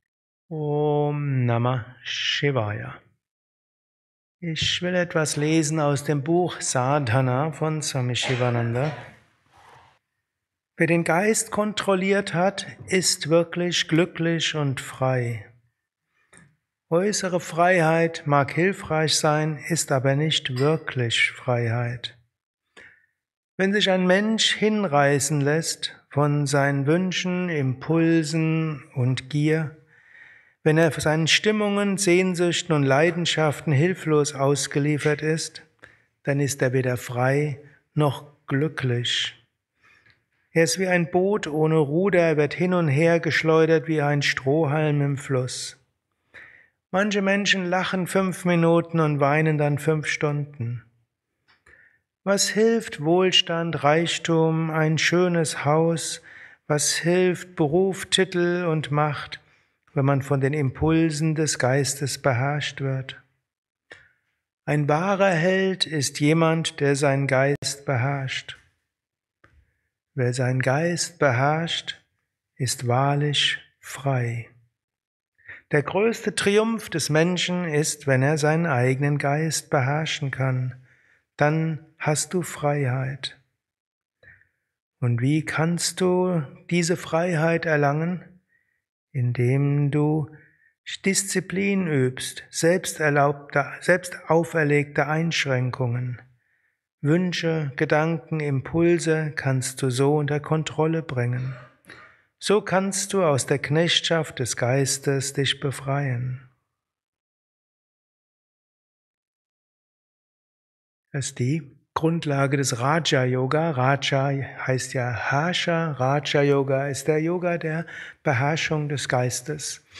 während eines Satsangs gehalten nach einer Meditation im Yoga